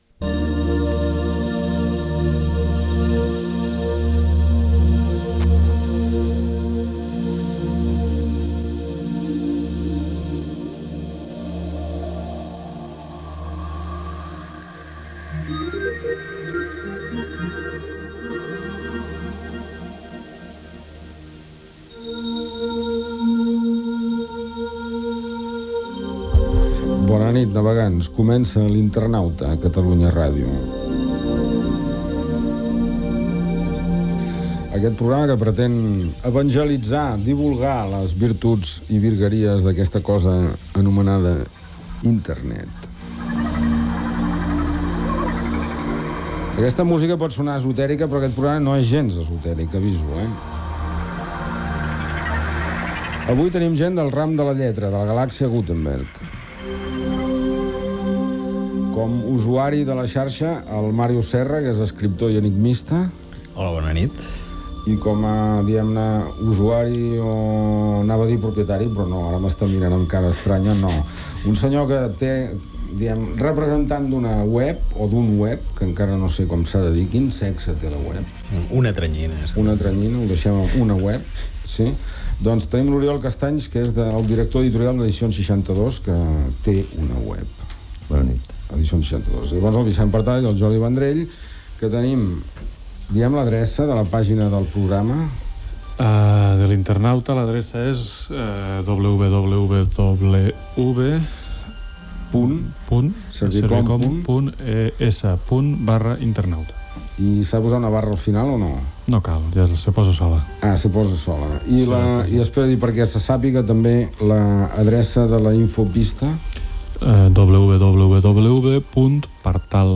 Sintonia, presentació inicial i dels invitats
Explicacions sobre com es navega per Internet i algunes paraules de l'argot Gènere radiofònic Divulgació